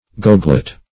goglet - definition of goglet - synonyms, pronunciation, spelling from Free Dictionary Search Result for " goglet" : The Collaborative International Dictionary of English v.0.48: Goglet \Gog"let\, n. [Pg. gorgoleta.]
goglet.mp3